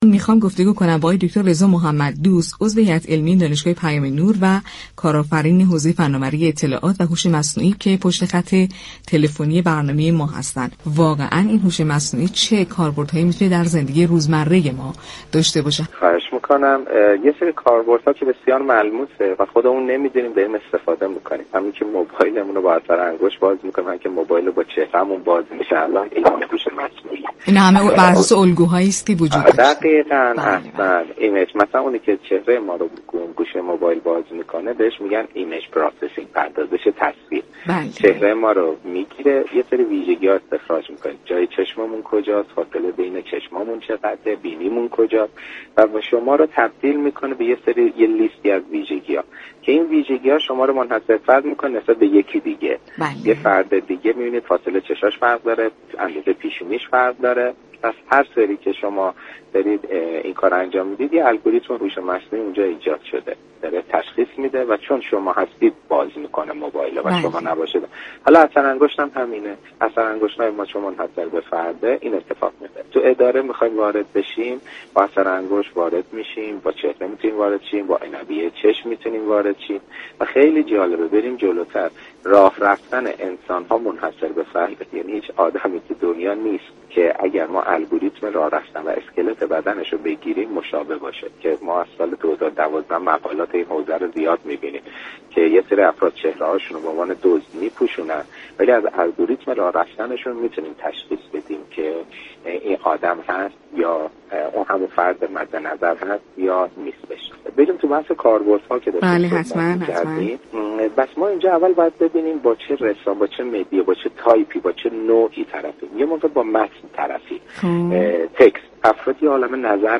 در گفت و گو با برنامه "علم بهتر است" رادیو تهران